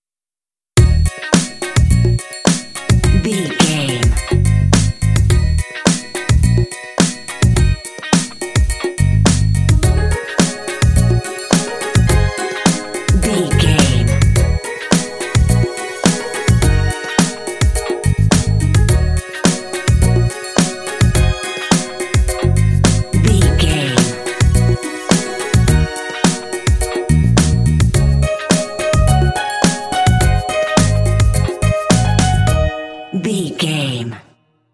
Ionian/Major
E♭
funky
happy
bouncy
groovy
synthesiser
bass guitar
drum machine
electric piano
electric guitar
Funk